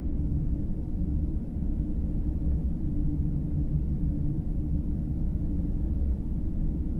livingquarters.ogg